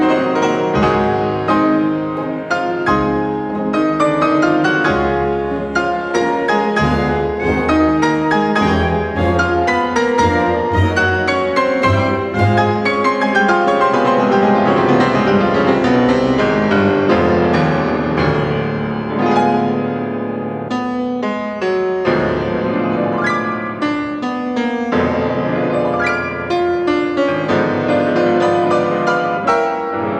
piano
0 => "Musique classique"